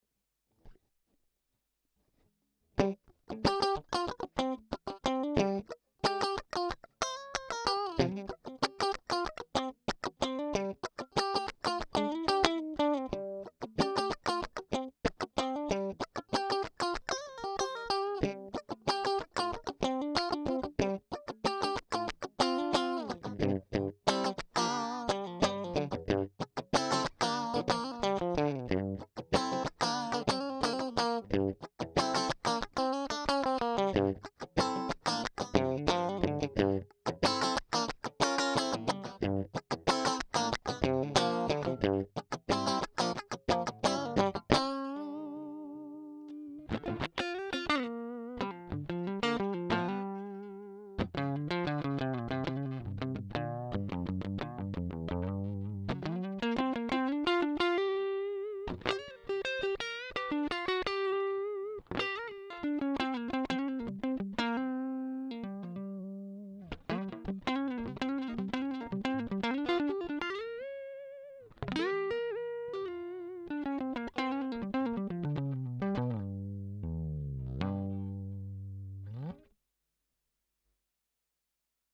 Electric Guitar (Stratocaster)
A US stratocaster directly recorded through the DI02.